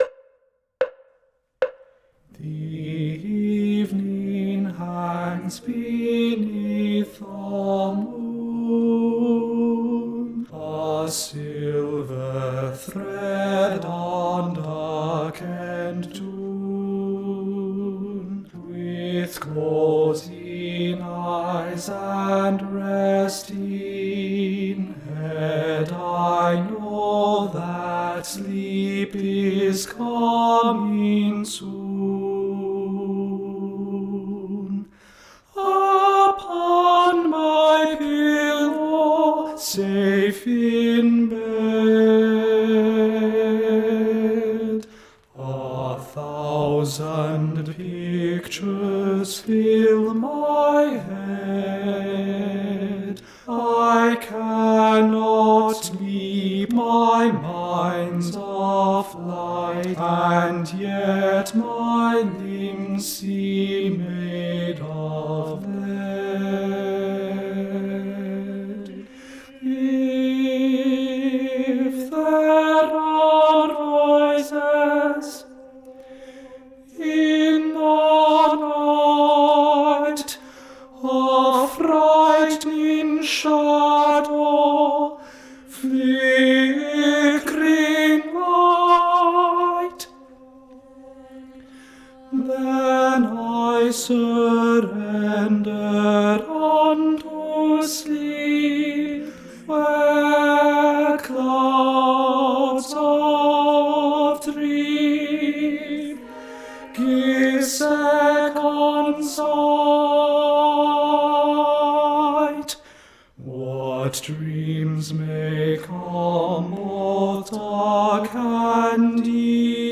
- Œuvre pour chœur à 8 voix mixtes (SSAATTBB)
Tenor 1 Live Vocal Practice Track